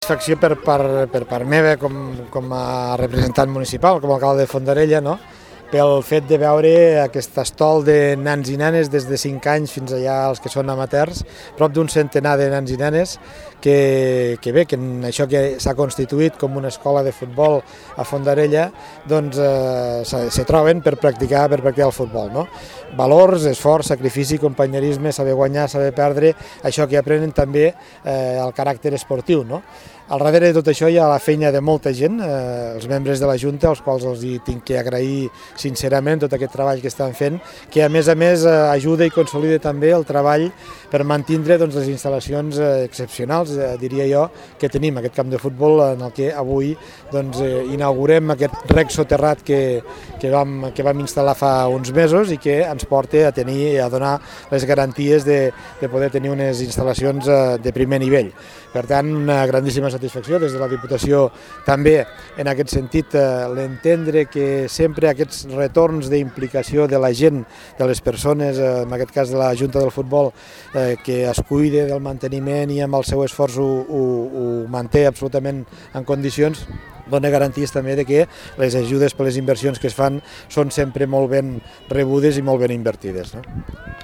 Tall-President-Fondarella.mp3